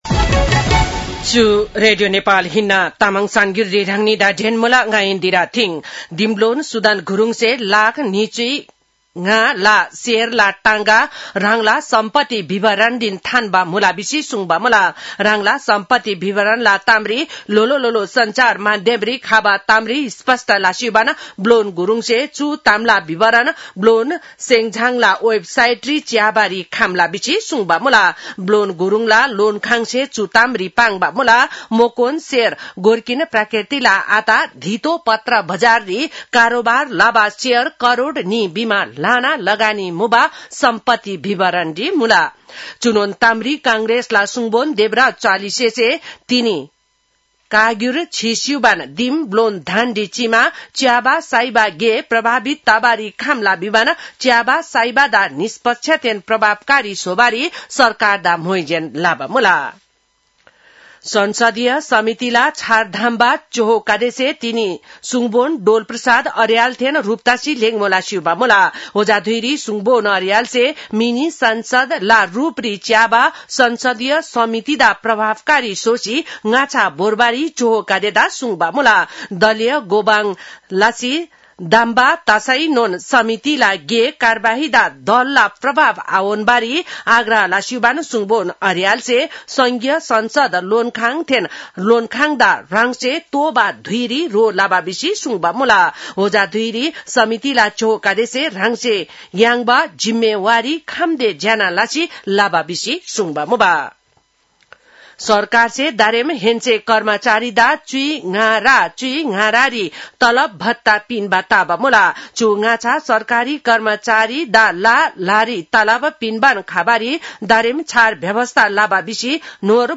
तामाङ भाषाको समाचार : ७ वैशाख , २०८३
Tamang-news-1-07.mp3